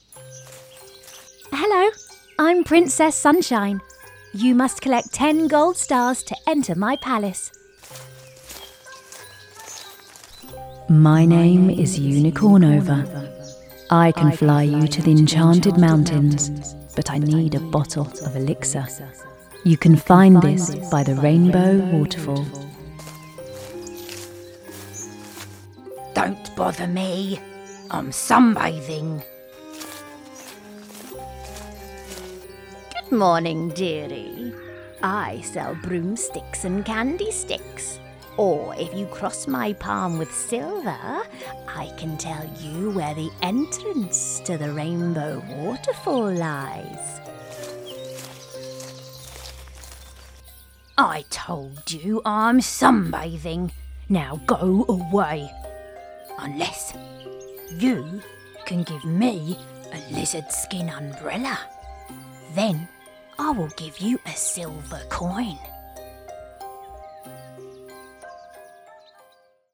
Smooth, Friendly, Cool and Recognisable
Fantasy Animation
Animation Game Character
RP ('Received Pronunciation')